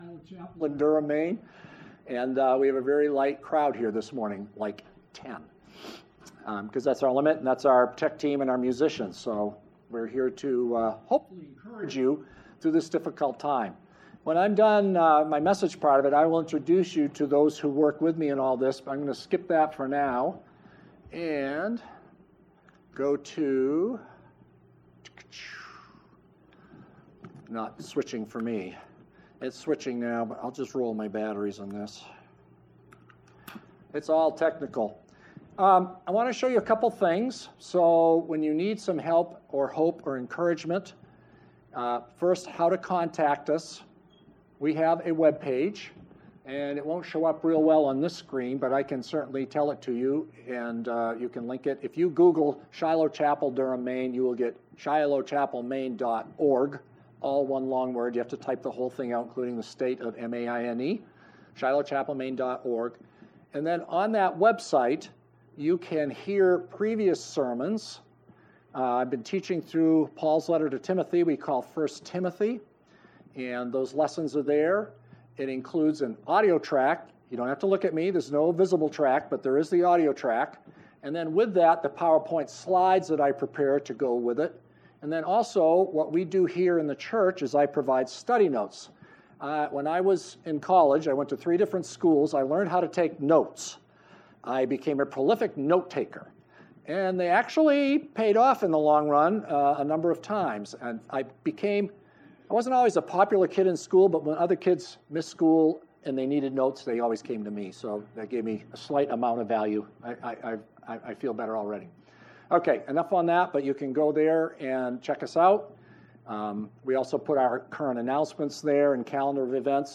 Sunday Morning Worship Service for March 22, 2020.